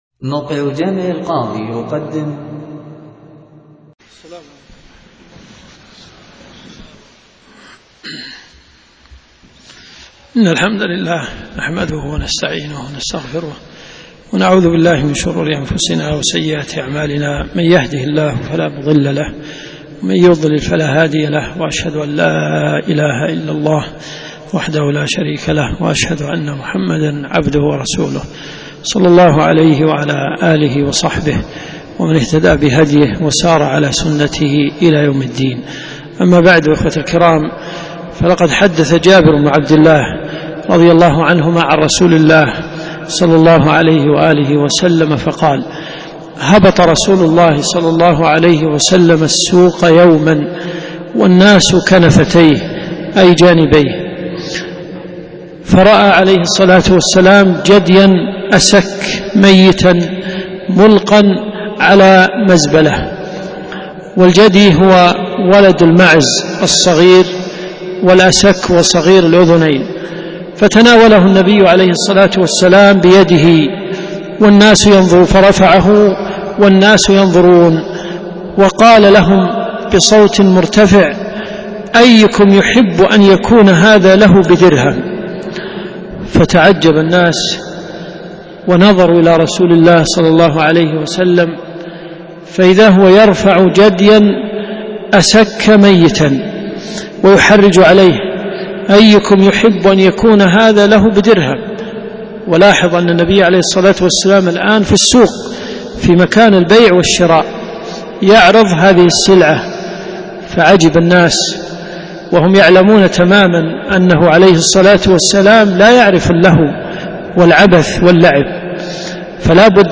4 - 1428 . قسم المحاضرات . الدنيا . جامع القاضي .